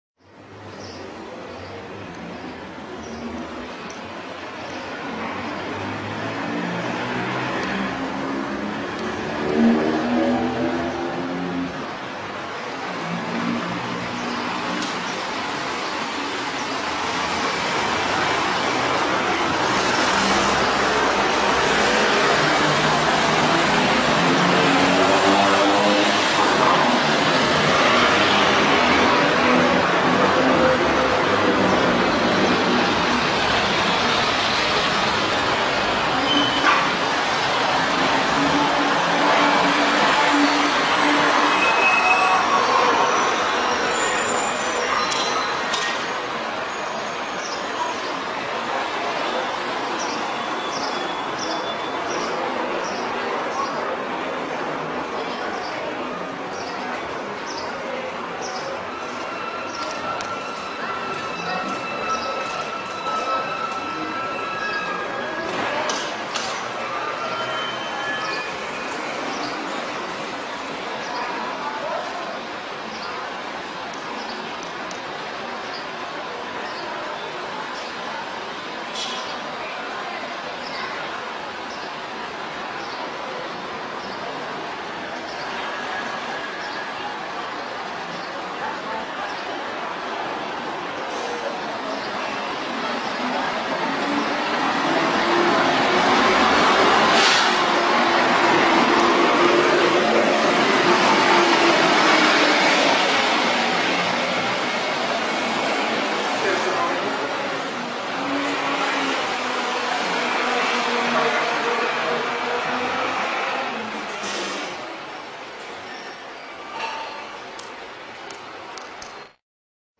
Tags: martillo